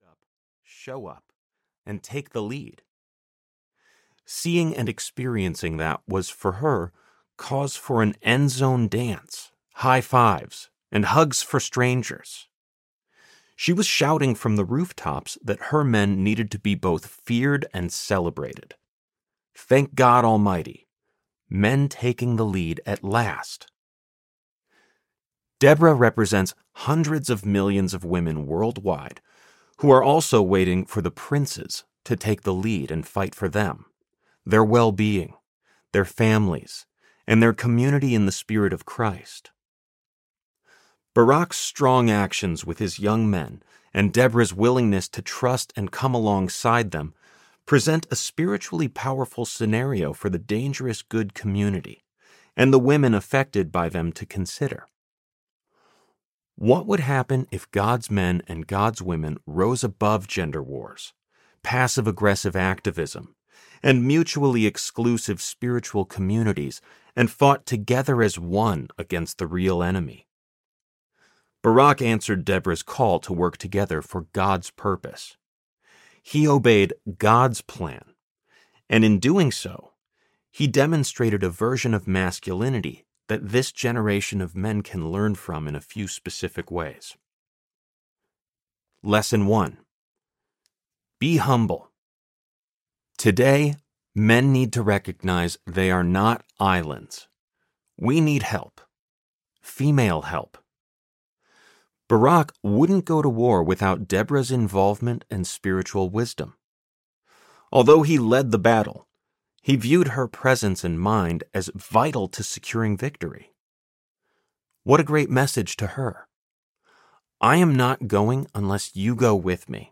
Dangerous Good Audiobook
5.72 Hrs. – Unabridged